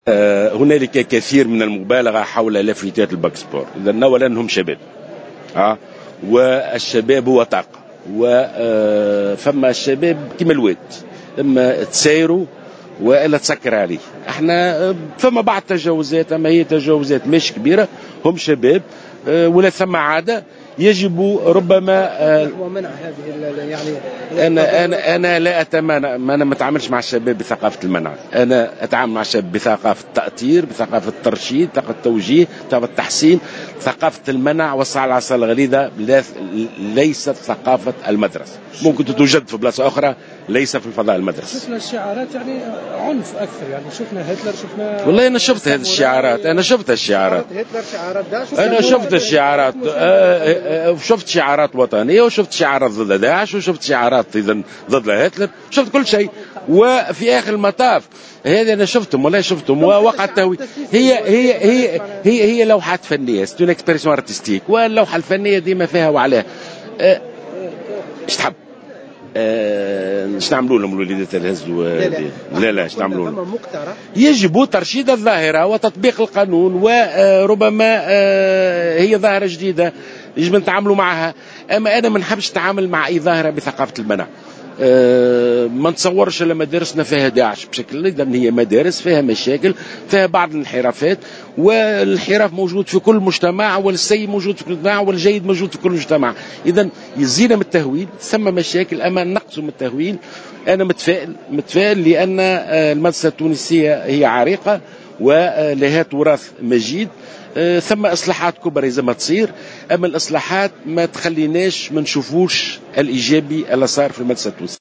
أكد وزير التربية ناجي جلول في تصريح اعلامي اليوم الخميس 23 أفريل 2015 في تعليق على لافتات الدخلة للbac sport أنه وقع تهويل المسألة والمبالغة في طرحها مضيفا أن هناك بعض التجاوزات ولكنها ليست كبيرة .